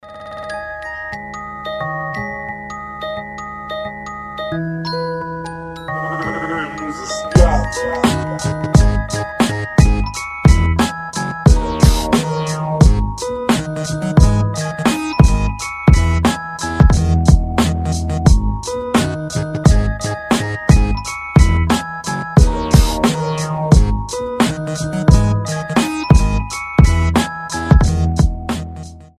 • Качество: 320, Stereo
рэп
без слов
инструментал